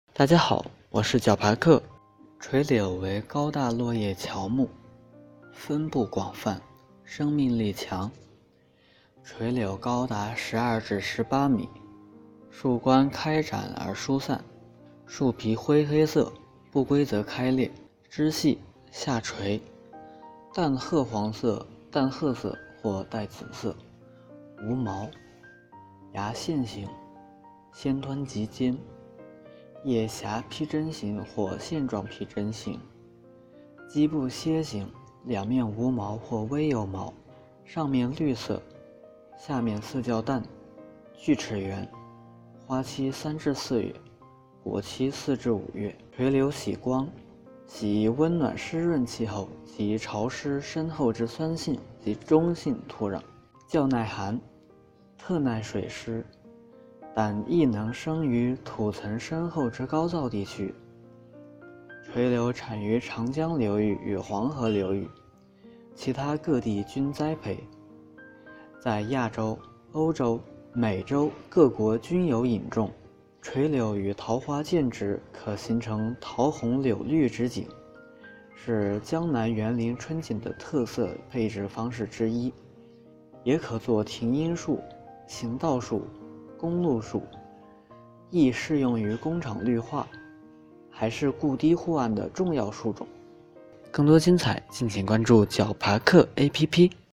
解说词: 垂柳（拉丁学名：Salix babylonica）为高大落叶乔木，分布广泛，生命力强。